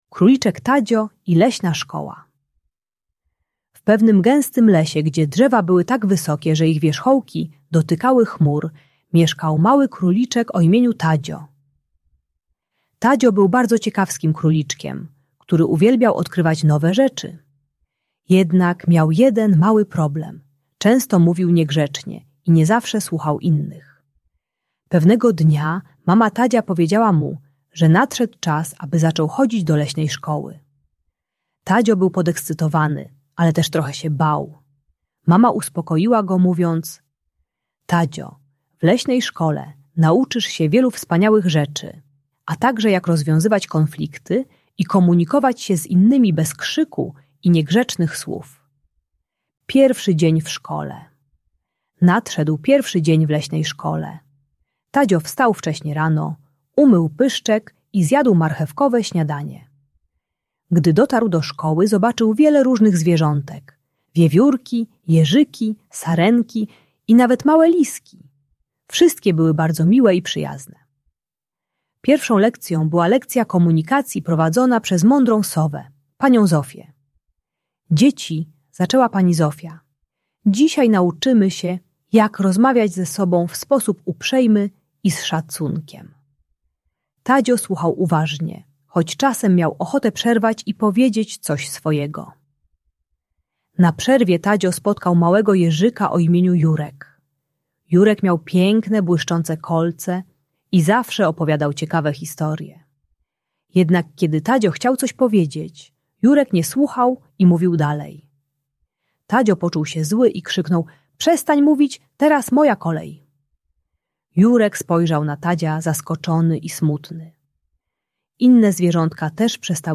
Historia Króliczka Tadzia i Leśnej Szkoły - Bunt i wybuchy złości | Audiobajka